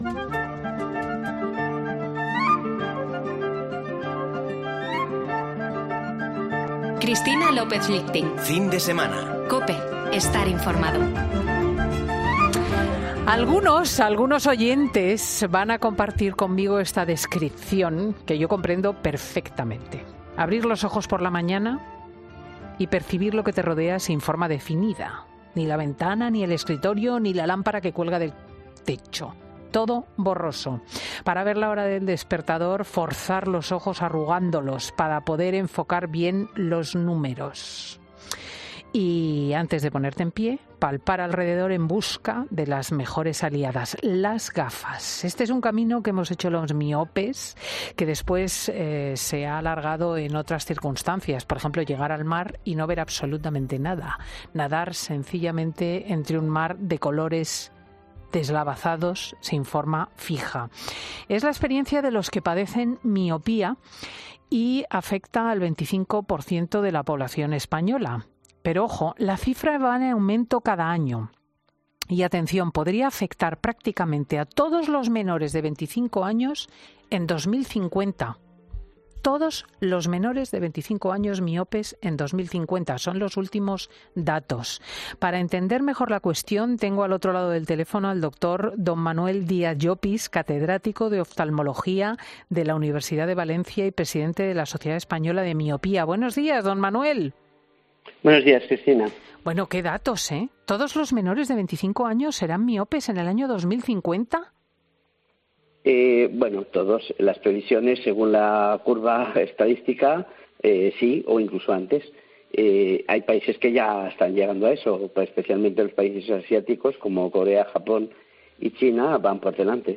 Redacción digital Madrid - Publicado el 11 sep 2022, 12:34 - Actualizado 18 mar 2023, 20:38 3 min lectura Descargar Facebook Twitter Whatsapp Telegram Enviar por email Copiar enlace Escucha ahora 'Fin de Semana' . "Fin de Semana" es un programa presentado por Cristina López Schlichting , prestigiosa comunicadora de radio y articulista en prensa, es un magazine que se emite en COPE , los sábados y domingos, de 10.00 a 14.00 horas.